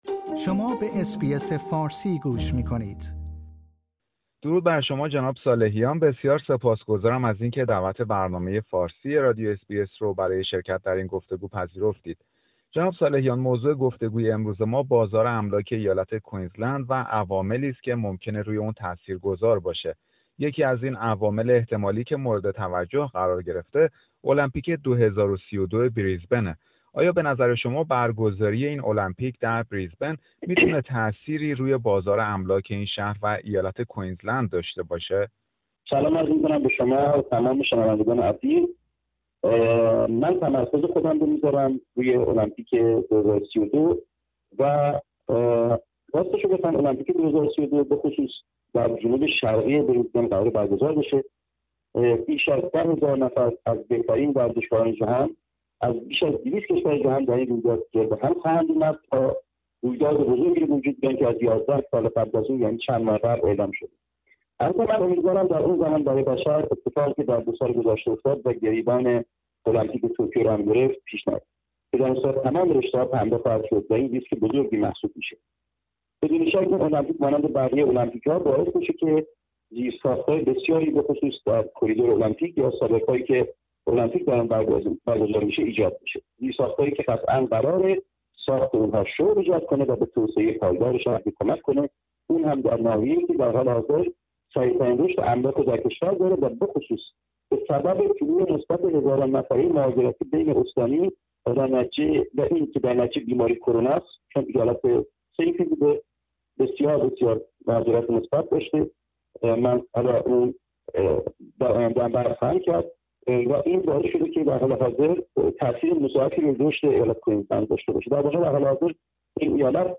مصاحبه رادیو SBS فارسی